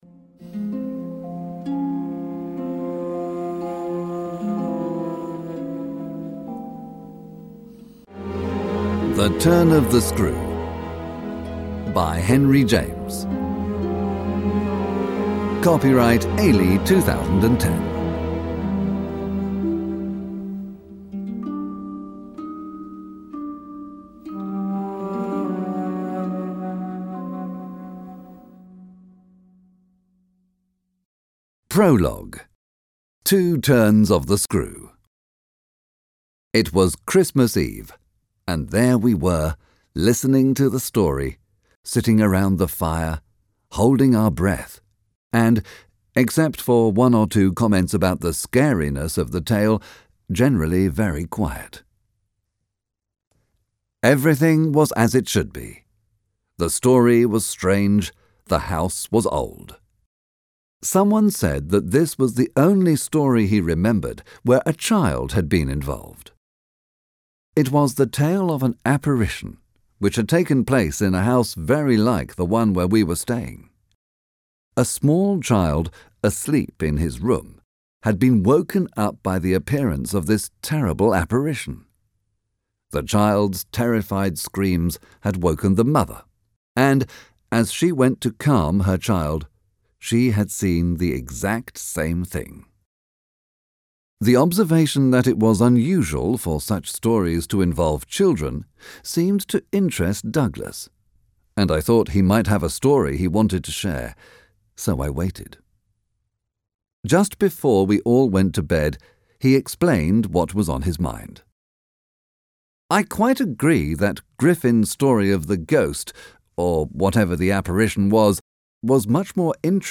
Obtížnost poslechu odpovídá jazykové úrovni B2 podle Společného evropského referenčního rámce, tj. pro studenty angličtiny na úrovni pokročilých.
AudioKniha ke stažení, 14 x mp3, délka 2 hod. 17 min., velikost 187,2 MB, česky